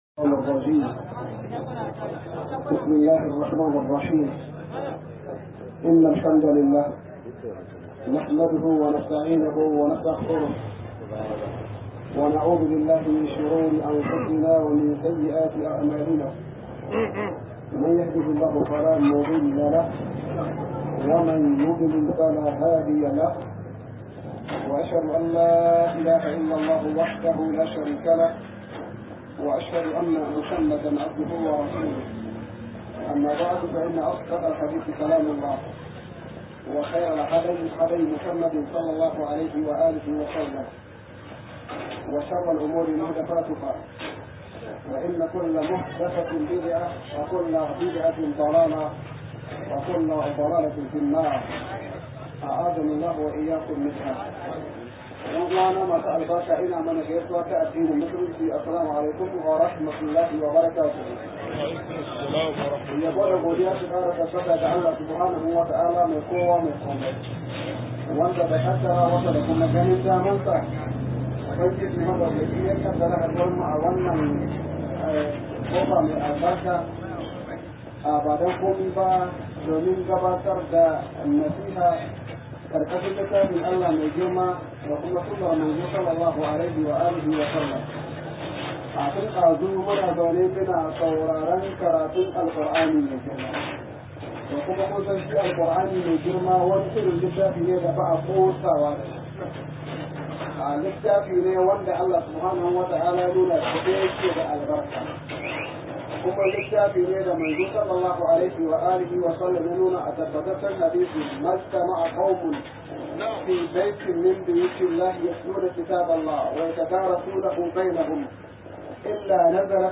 202-Wasu Kurakurai a Tarbiyya - MUHADARA